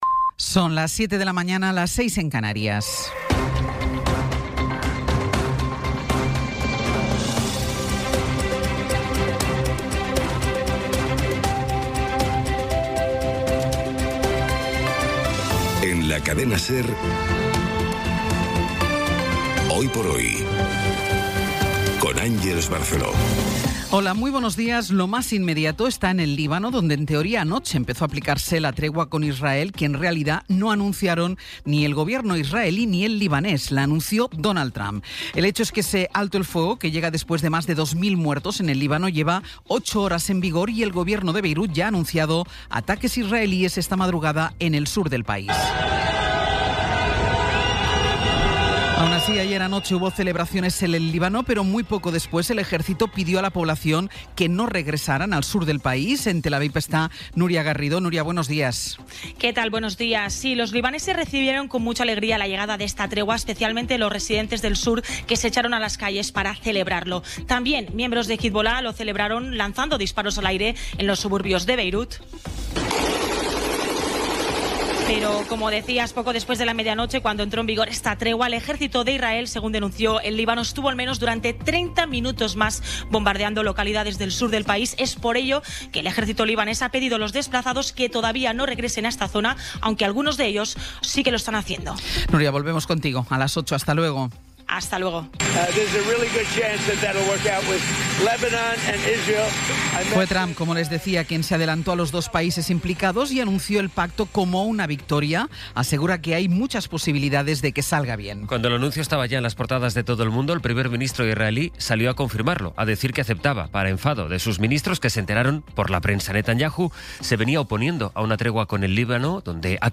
Resumen informativo con las noticias más destacadas del 17 de abril de 2026 a las siete de la mañana.